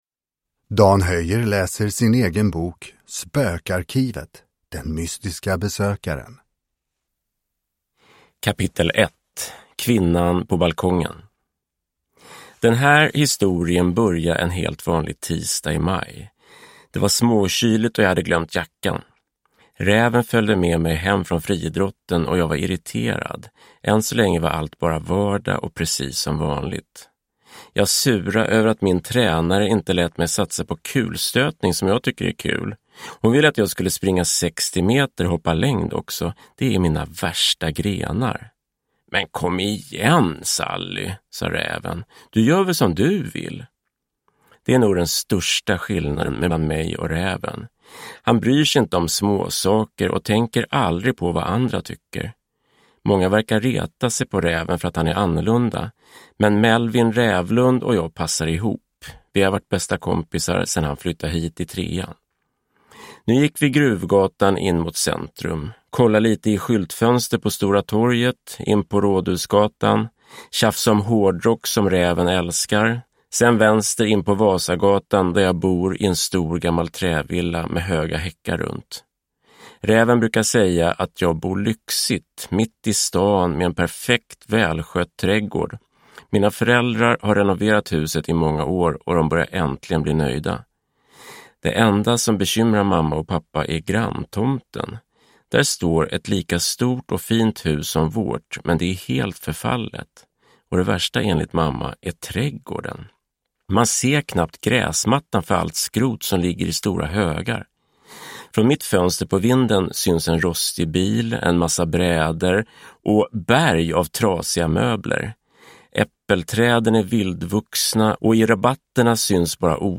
Den mystiska besökaren – Ljudbok – Laddas ner